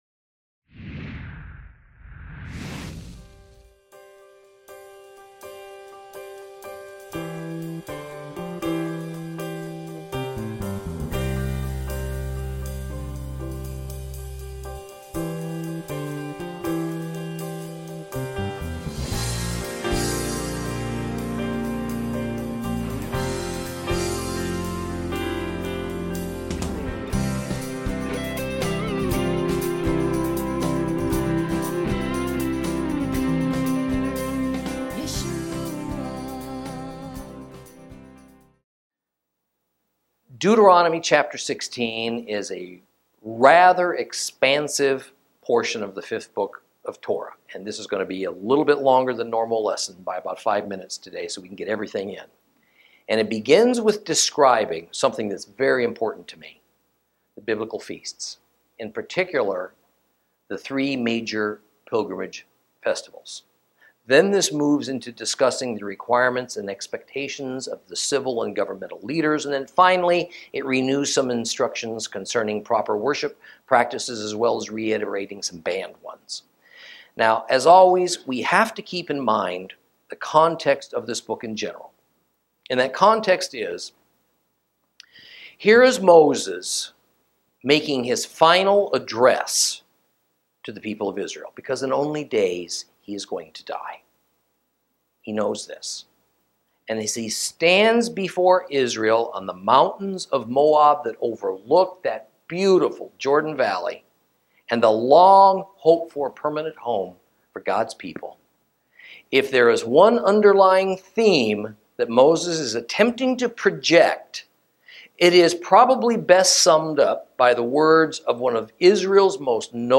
Lesson 20 Ch16 - Torah Class